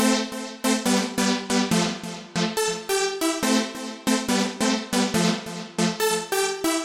大型恍惚合成器
描述：大型恍惚合成器
Tag: 140 bpm Trance Loops Synth Loops 1.15 MB wav Key : A